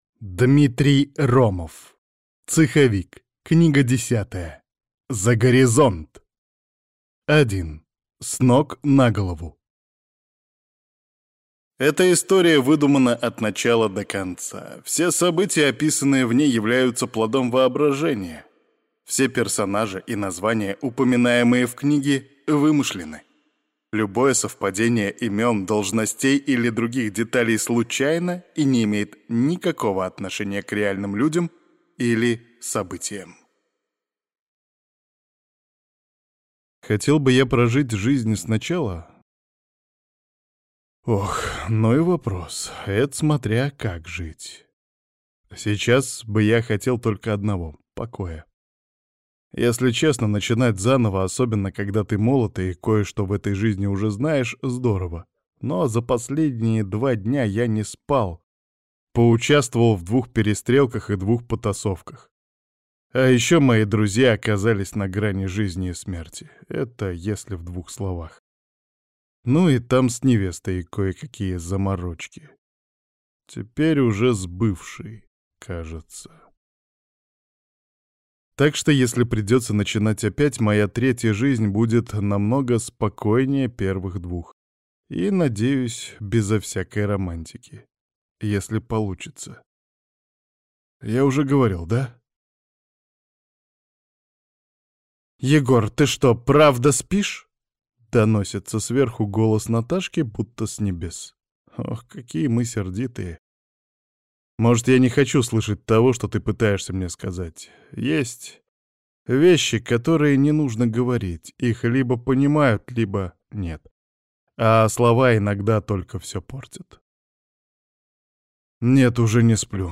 Аудиокнига ⚒Альтернативная история, Назад в СССР, Попаданцы ✔ 10 книга из 15 в серии «Цеховик» Новый этап, новые цели, ну, и в отношениях тоже кое-что новое.